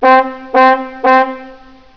Small Ship Horns
Small ‘toot-toot’ - Cabin cruiser horn